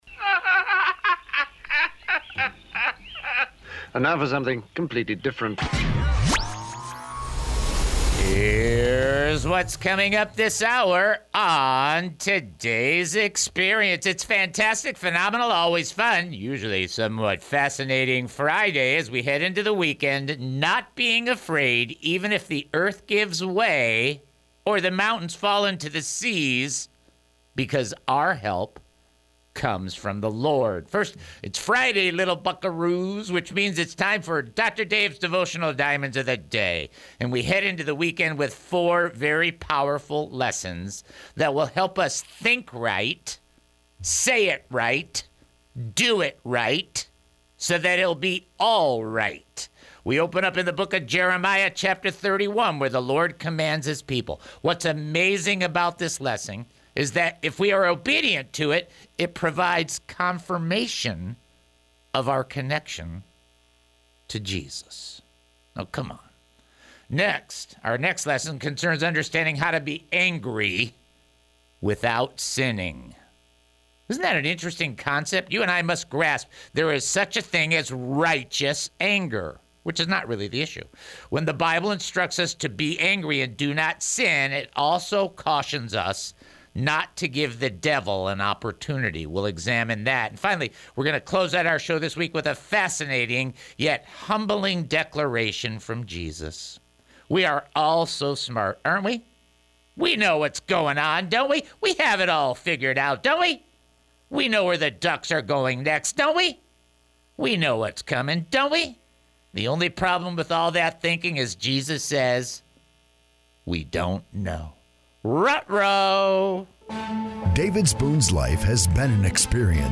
This segment underscores that circumstances should not dictate our lives; rather, Jesus as Lord provides stability and salvation from both sin and situational chaos, ensuring we remain unshaken when we stand on Him. Engaging the Word: Trivia and Teaching The program blends interactive trivia with deep biblical insights to educate and engage its audience.